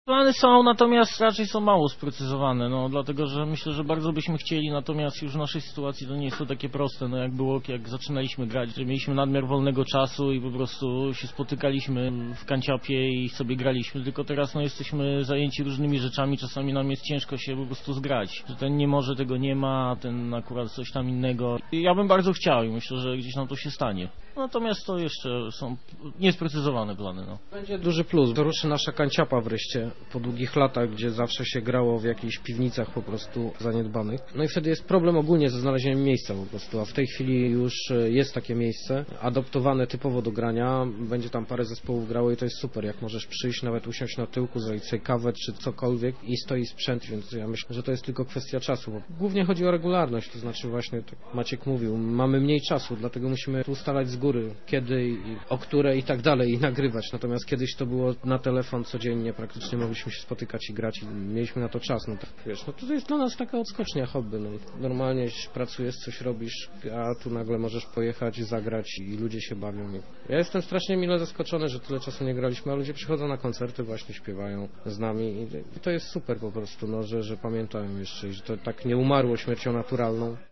Wywiad
przeprowadzony po koncercie grupy Starzy Singers, 30 pa�dziernika 2005 w klubie Jazzga, w �odzi.